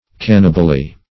Search Result for " cannibally" : The Collaborative International Dictionary of English v.0.48: Cannibally \Can"ni*bal*ly\, adv. In the manner of cannibal.
cannibally.mp3